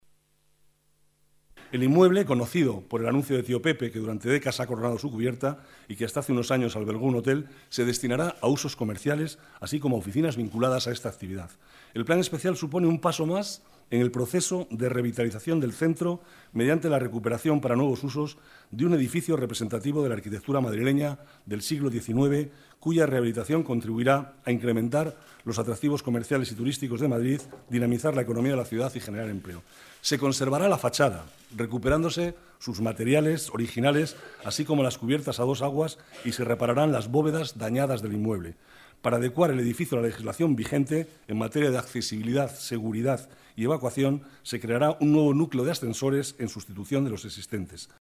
Nueva ventana:Declaraciones de Manuel Cobo sobre el futuro del edificio 'Tío Pepe'